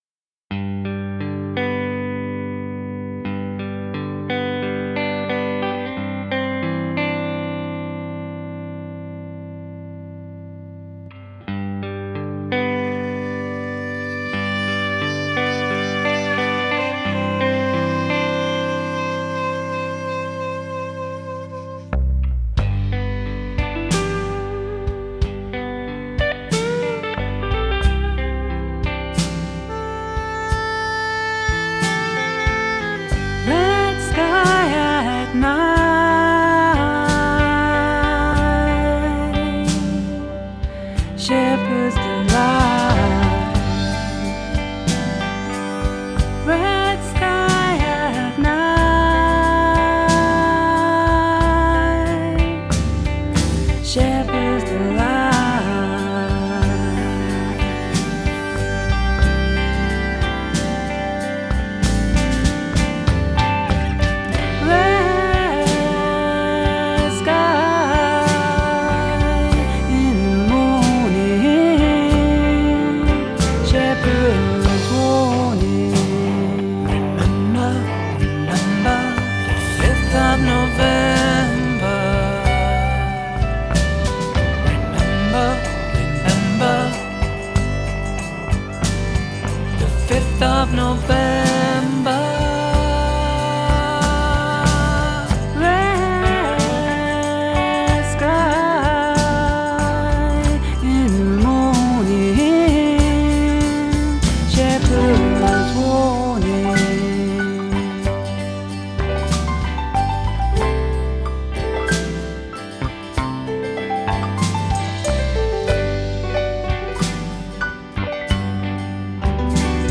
TraditionalLullaby